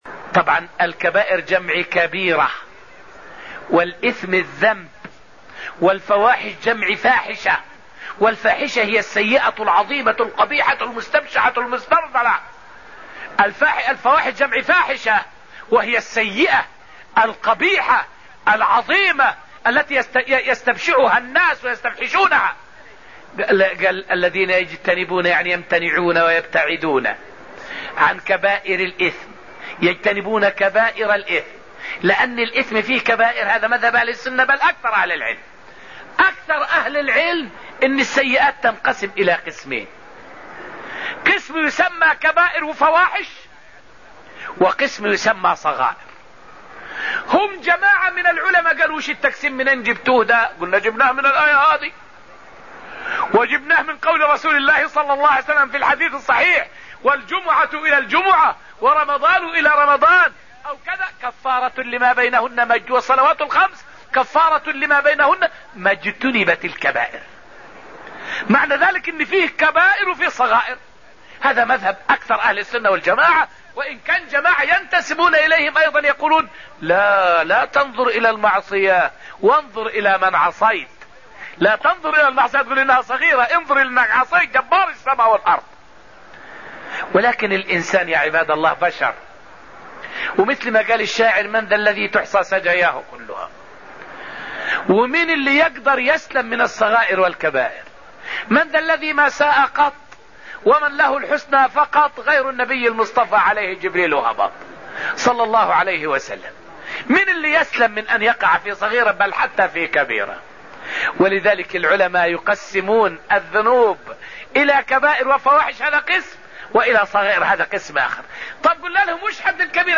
فائدة من الدرس العاشر من دروس تفسير سورة النجم والتي ألقيت في المسجد النبوي الشريف حول السيئات وأقسامها وخلاف العلماء فيها.